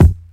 • 90s Smooth Rap Kick Drum Sample F Key 95.wav
Royality free bass drum single hit tuned to the F note. Loudest frequency: 211Hz
90s-smooth-rap-kick-drum-sample-f-key-95-wJf.wav